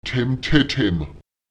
Lautsprecher temte(m)tem [tEmÈtEtEm] fünfhundertelf („siebensiebensieben“)